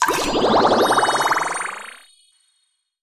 water_bubble_spell_heal_05.wav